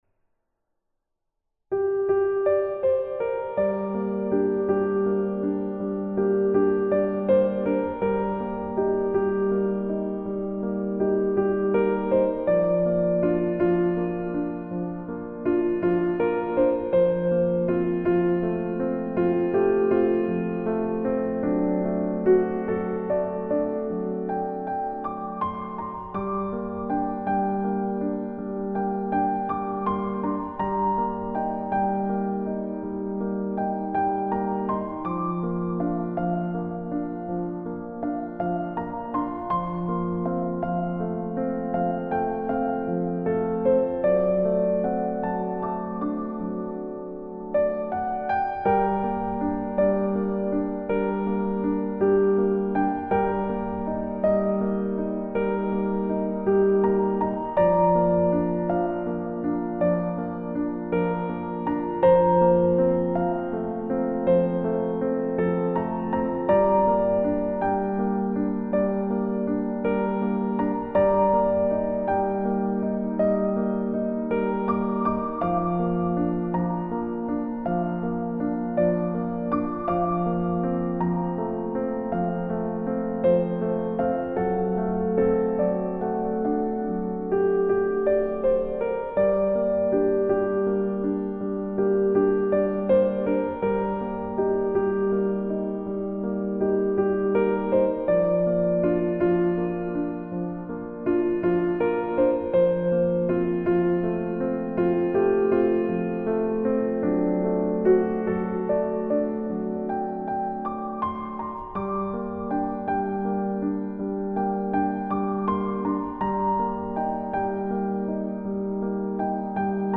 Instrumentation : Piano
Genre:  Relaxation